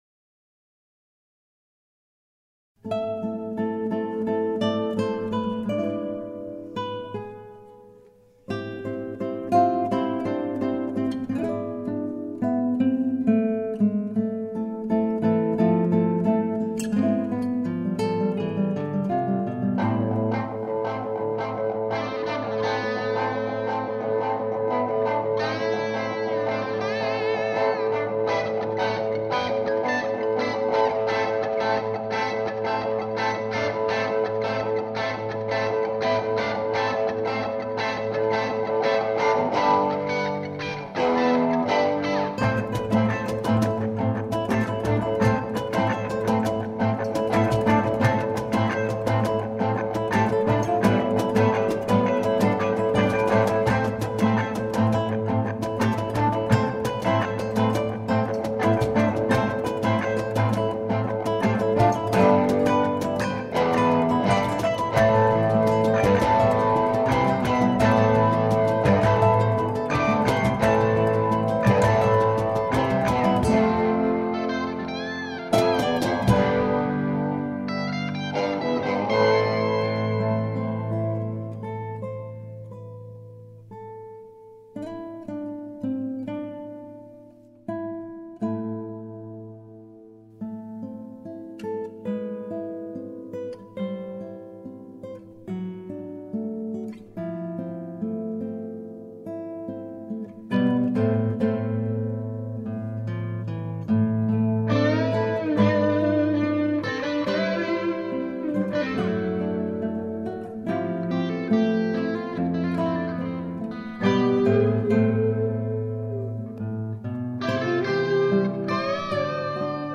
GUITARRA ELÉCTRICA E COROS.
GUITARRA ESPAÑOLA E COROS.
CAIXÓN FLAMENCO E INSTRUMENTOS DE PEQUENA PERCUSIÓN.